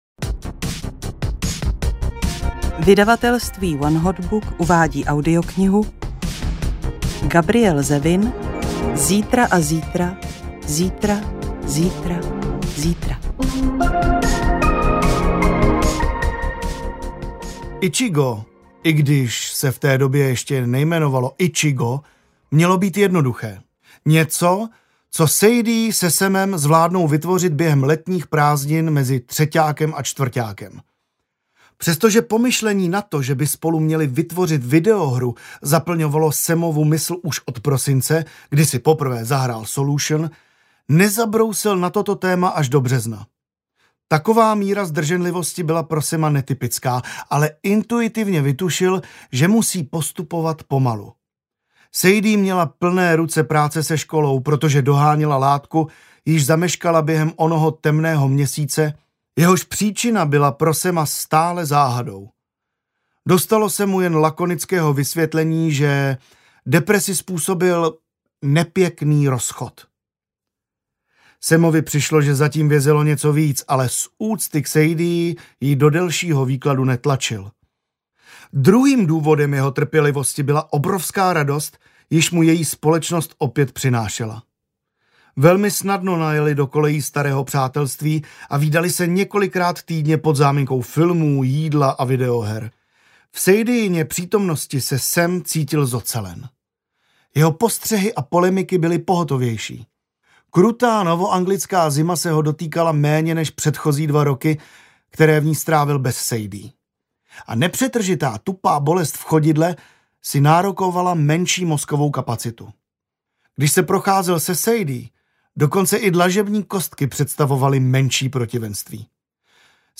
Ukázka z knihy
zitra-a-zitra-zitra-zitra-zitra-audiokniha